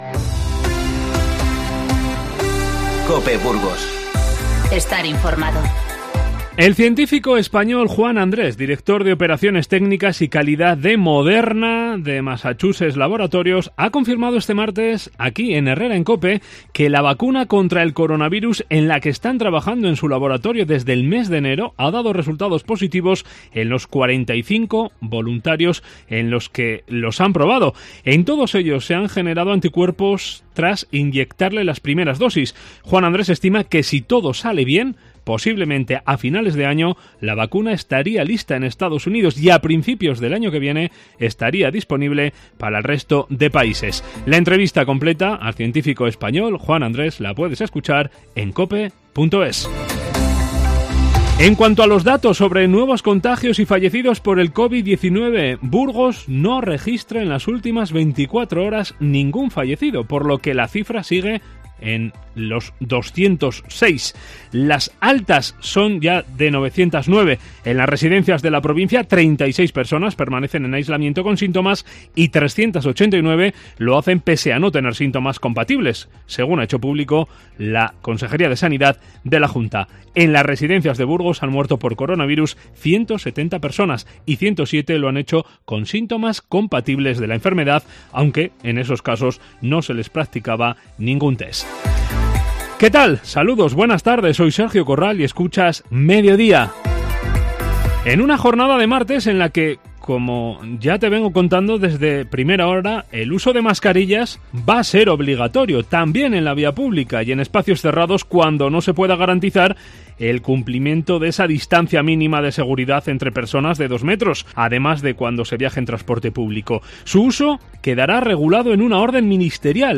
INFORMATIVO MEDIODÍA 19/5